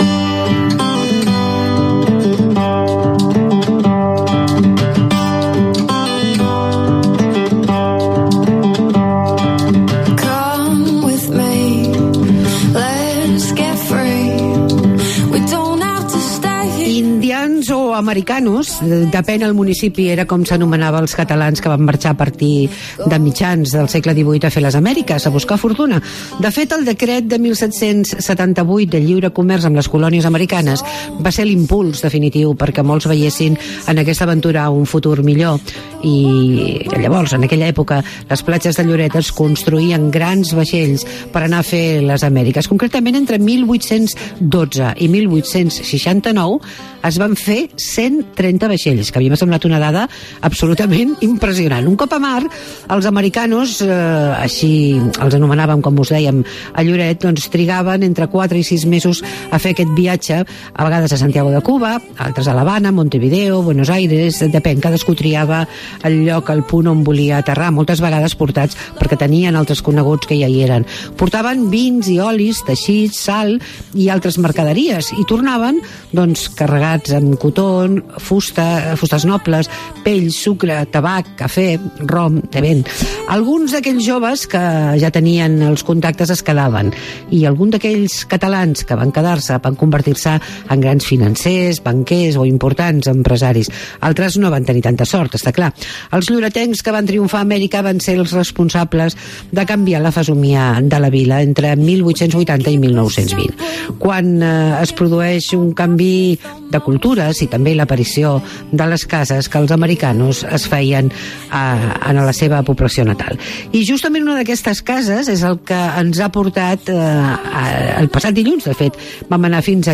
Fem una passejada pel Lloret d'hivern, visitem les cases dels americanos i entrevistem a l' alcalde Jaume Dulsat.